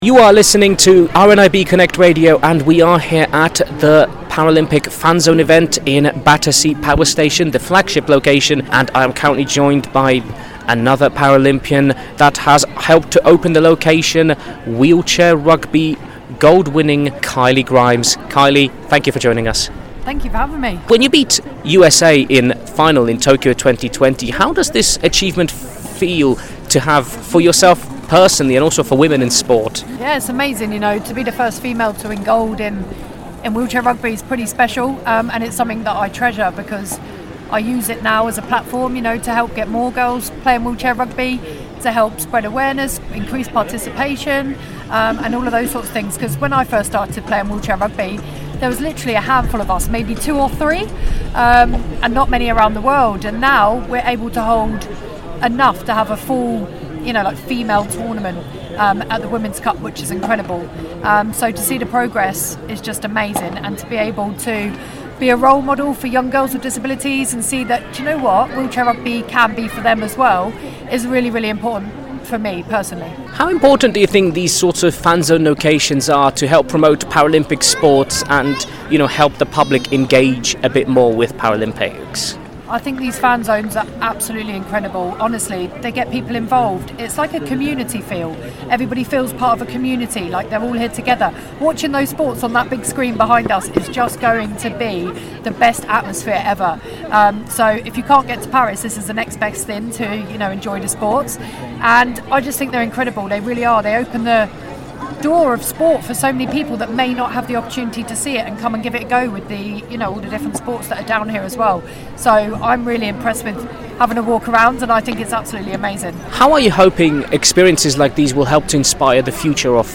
RNIB - See Sport Differently / Paralympic Fan Zone At Battersea Power Station